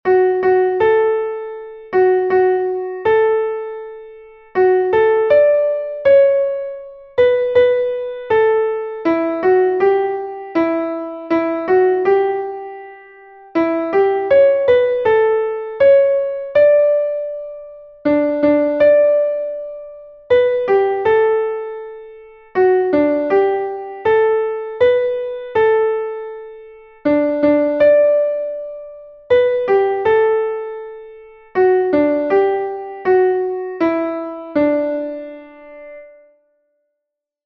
guten-abend-gut-nacht_klavier_melodiemeister.mp3